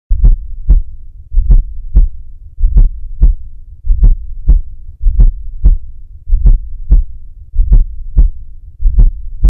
Heart Sounds
S4_slow.m4a